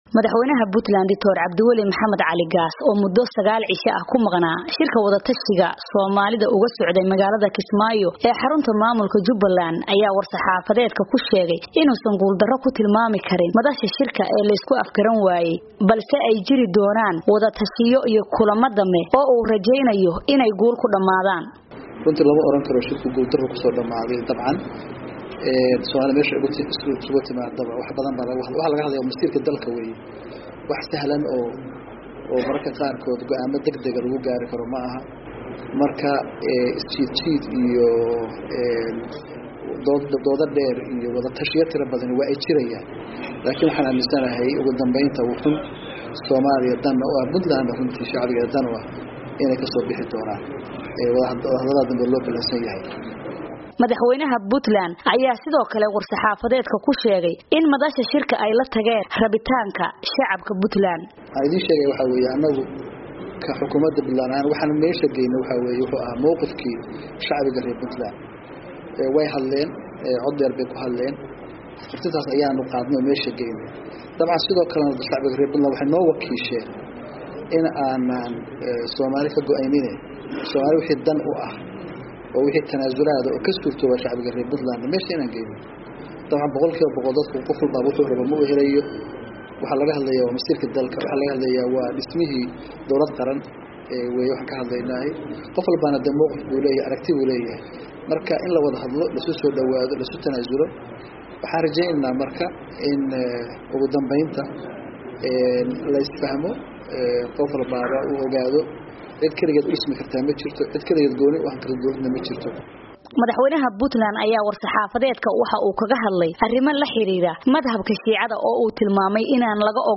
Gaas oo shir jaraa’id ku qabtay magaalada Boosaaso, oo uu maanta tagay, ayaa sheegay in shirka uusan guul darro ku dhammaan, balse lagu kala maqan yahay wadatashiyo.